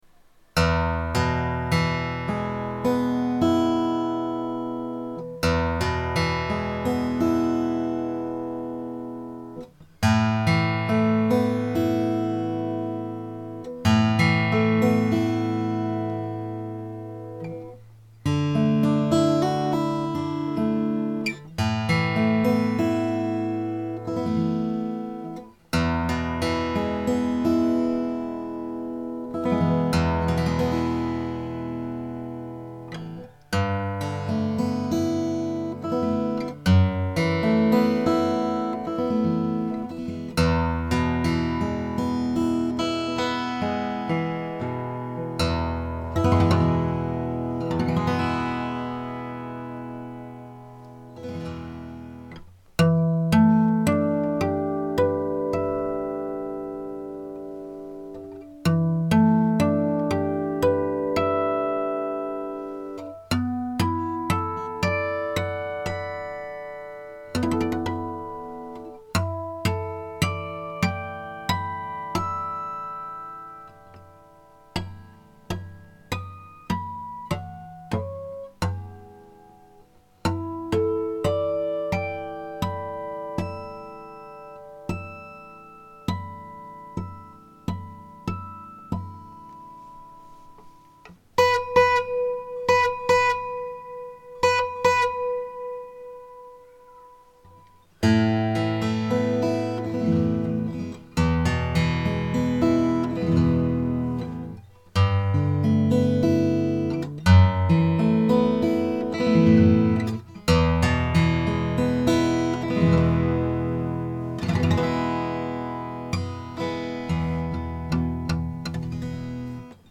マーチンOOO-18の1930年代です。この音のすごさは録音しきれませんでした。